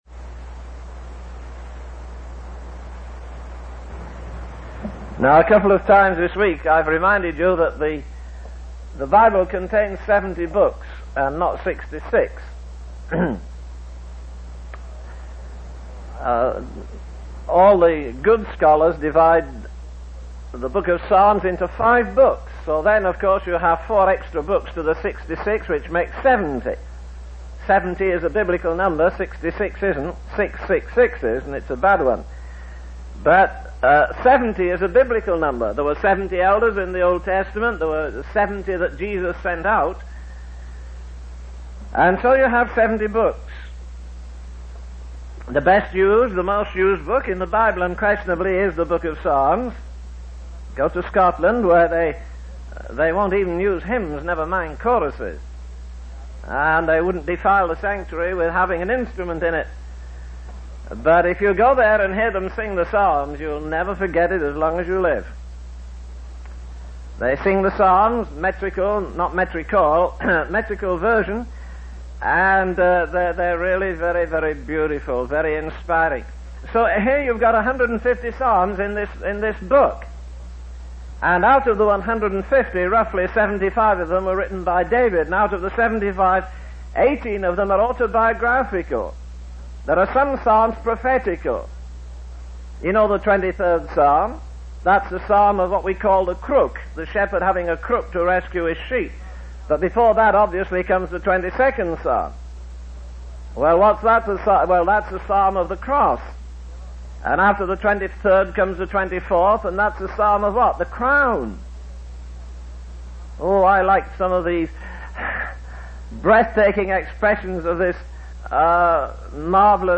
In this sermon, the speaker emphasizes that worldly pursuits and pleasures will never satisfy the human heart.